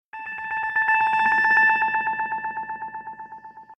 Sci-Fi.ogg